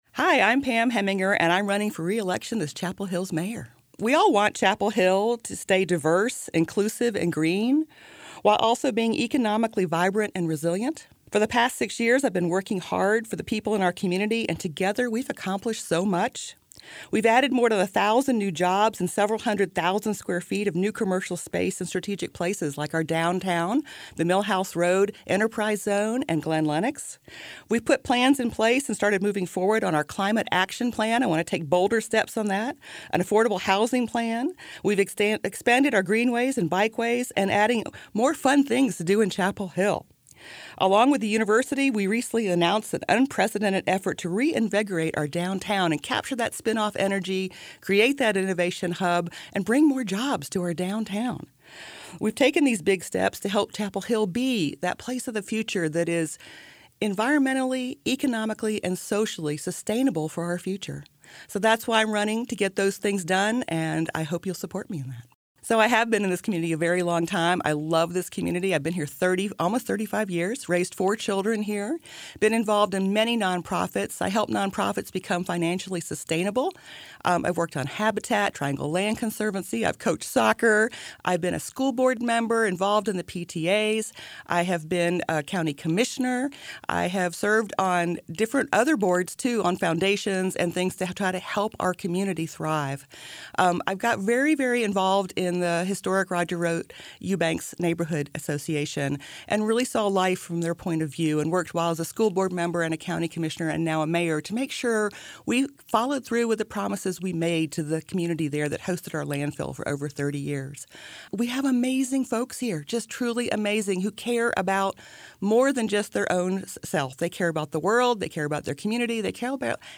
During this local election season, 97.9 The Hill and Chapelboro spoke with candidates for each of the races in Chapel Hill, Carrboro and Hillsborough. Each answered the same set of questions regarding their decision to run for elected office, their background in the community and what they wish for residents to think of when voting this fall.